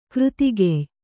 ♪ křtigey